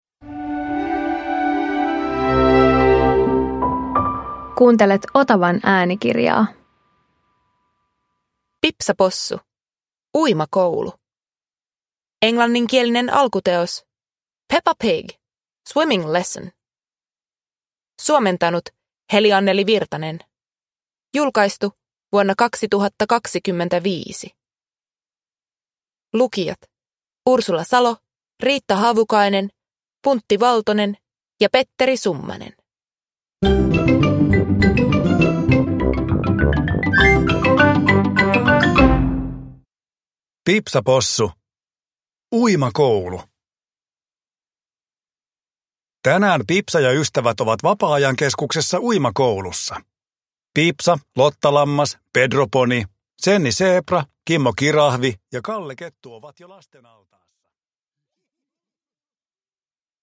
Pipsa Possu - Uimakoulu – Ljudbok